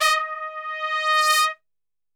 D#3 TRPSWL.wav